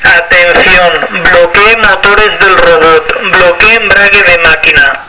Módulo vocal
Posibilidad de grabar y reproducir hasta 15 archivos de sonido (mensajes, tonos
88dB
K71-MODULO VOCAL